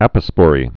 (ăpə-spôrē, ə-pŏspə-rē)